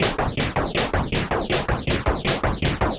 RI_RhythNoise_80-03.wav